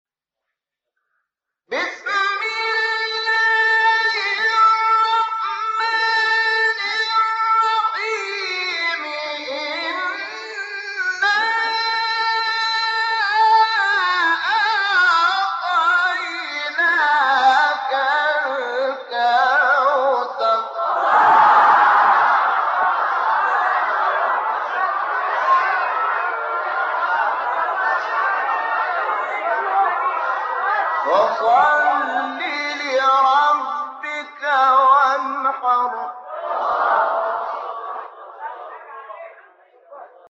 گروه شبکه اجتماعی: فرازهایی از تلاوت قاریان بنام کشور مصر ار جمله شیخ رفعت، احمد صالح، عبدالفتاح شعشاعی را می‌شنوید.